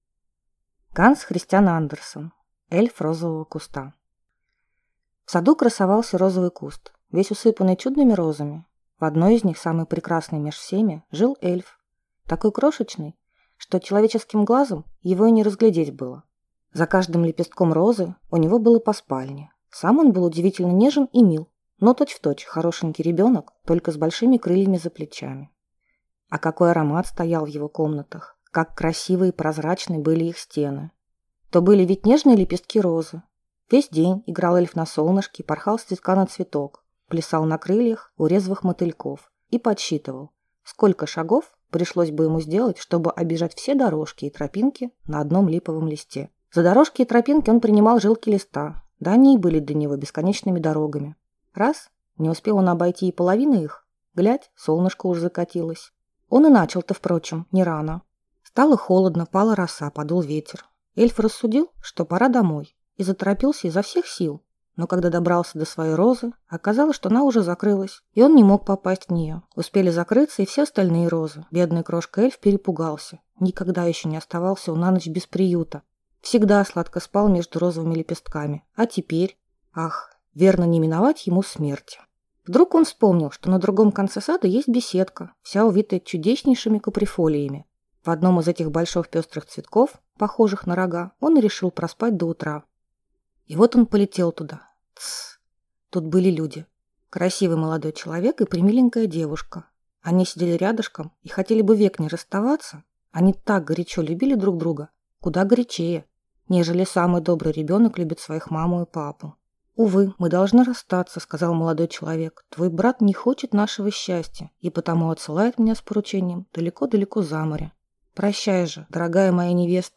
Аудиокнига Эльф розового куста | Библиотека аудиокниг